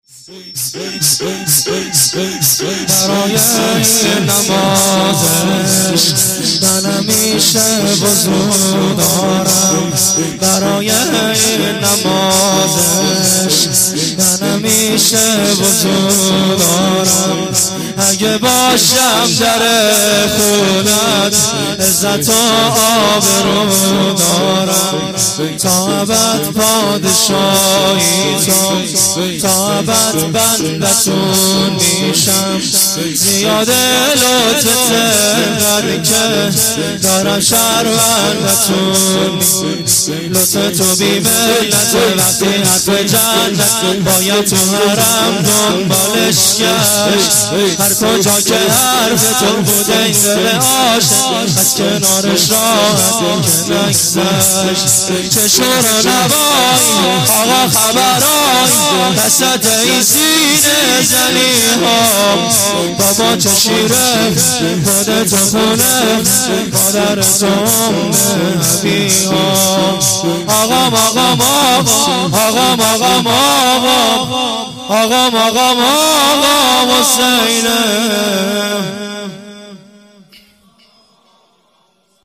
شور - برای این نماز عشق